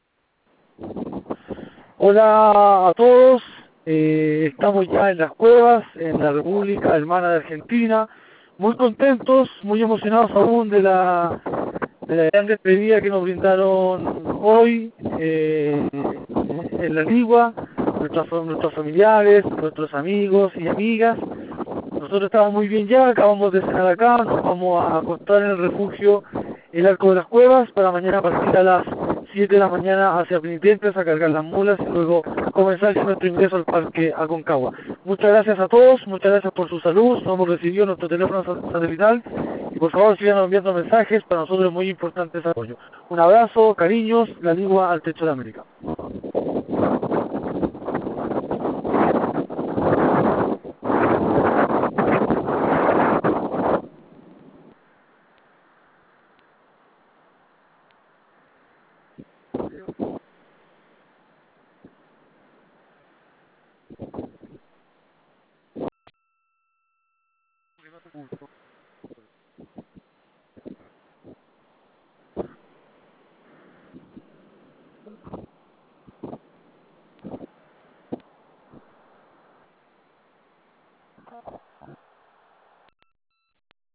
Dispatch #4 from Las Cuevas, Argentina(3180)